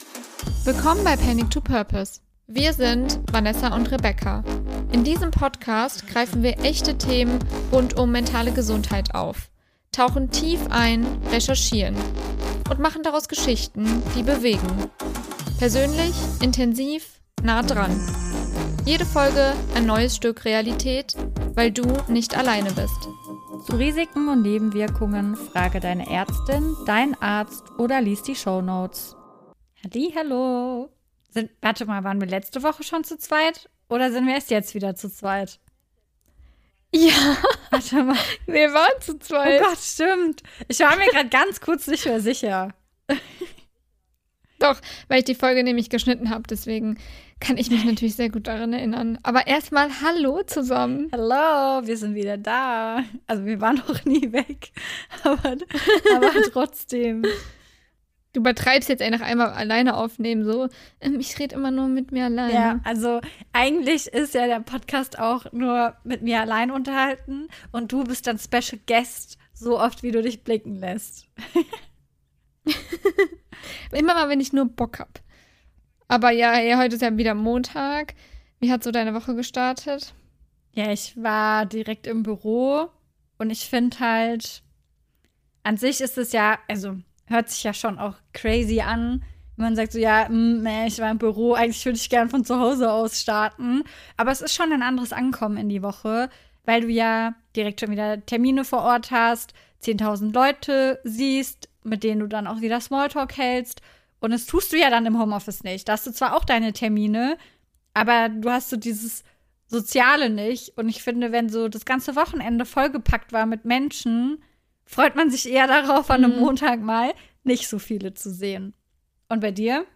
Zwischen den erzählten Szenen sprechen wir über unsere eigenen Erfahrungen, wissenschaftliche Fakten und die mentale Seite des Zyklus.